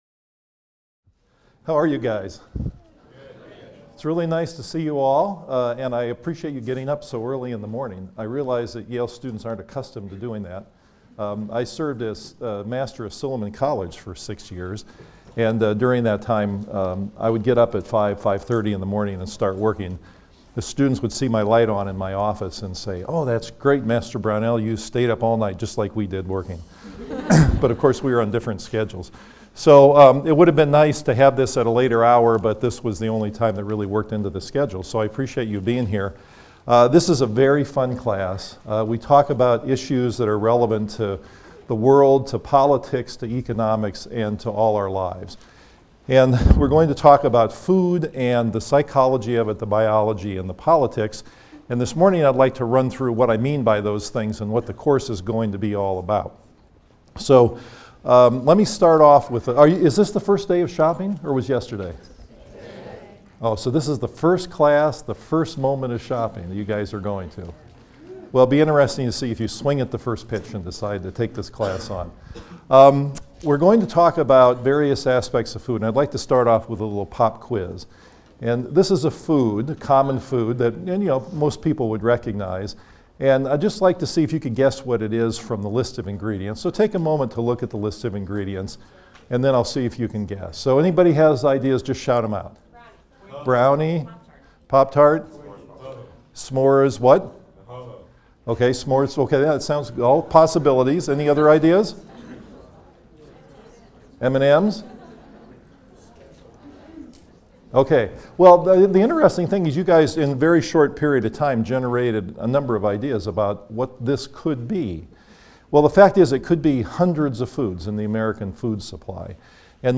PSYC 123 - Lecture 1 - Introduction: What We Eat, Why We Eat and the Key Role of Food in Modern Life | Open Yale Courses